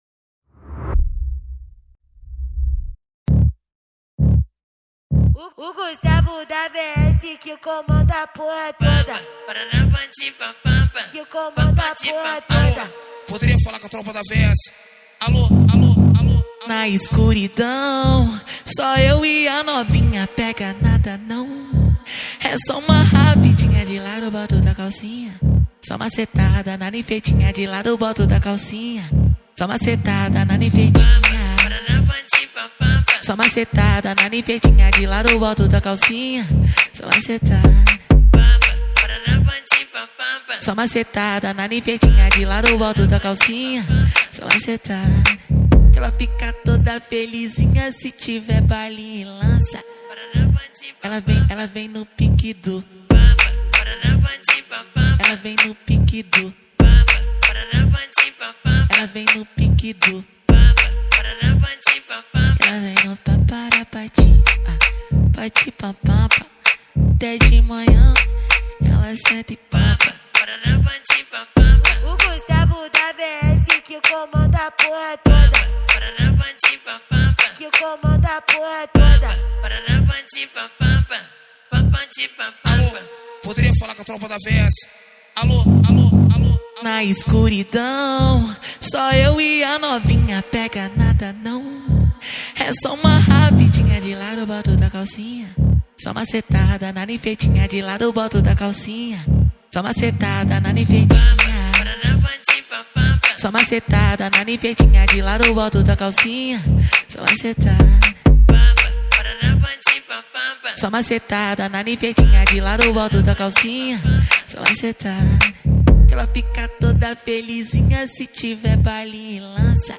2024-10-22 20:09:07 Gênero: Phonk Views